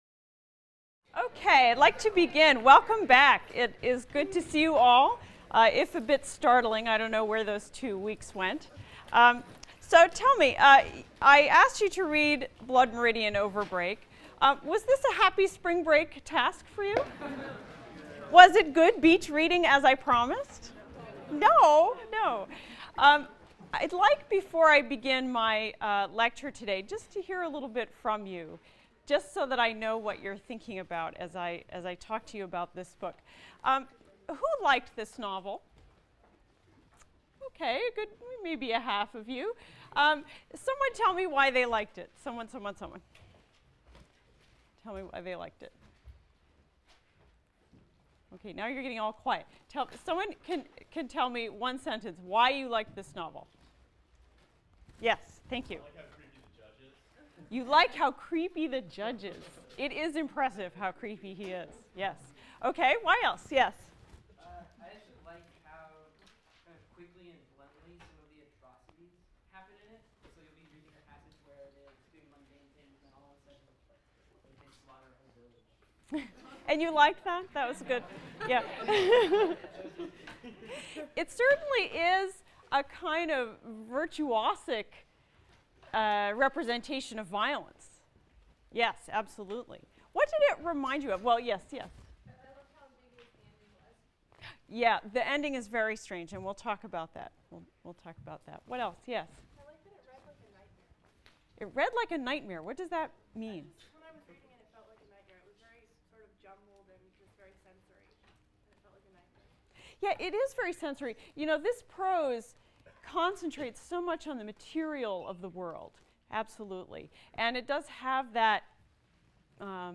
ENGL 291 - Lecture 17 - Cormac McCarthy, Blood Meridian | Open Yale Courses